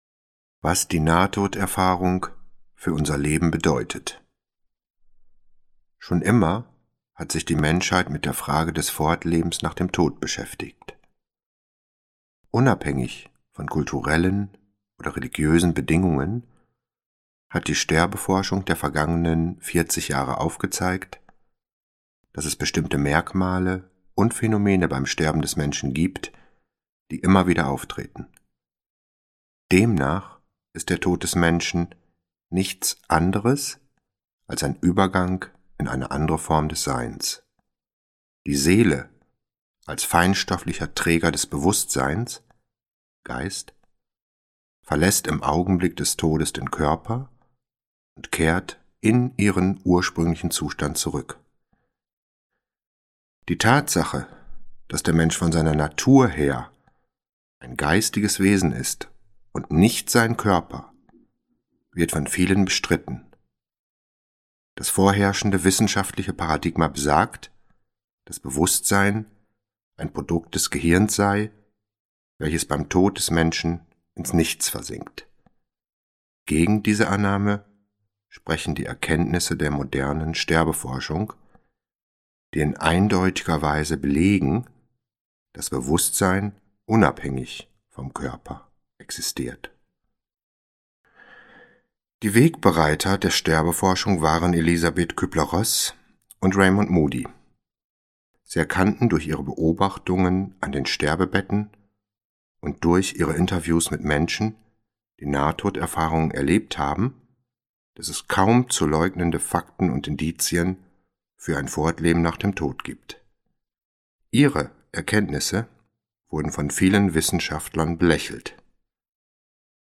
Begegnungen mit dem Licht - Bernhard Jakoby - Hörbuch